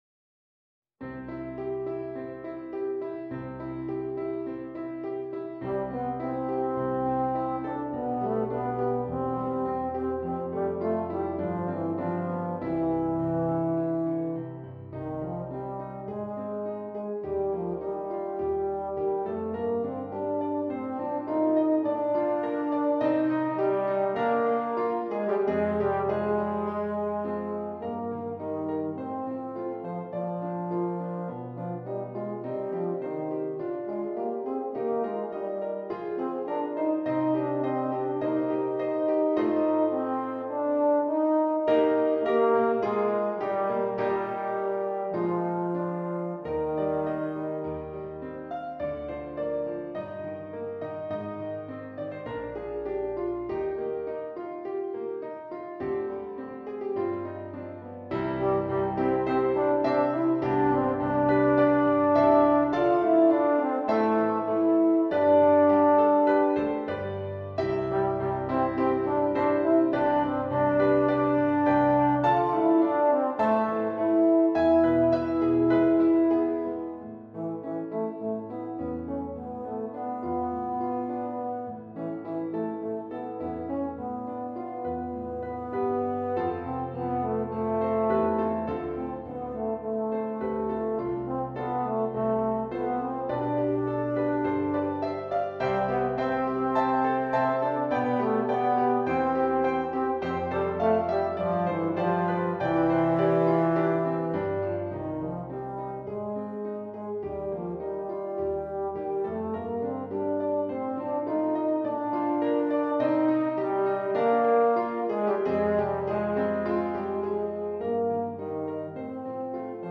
Besetzung: Euphonium Solo & Piano, , Euphonium Solo & Piano)